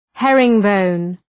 Shkrimi fonetik {‘herıŋ,bəʋn}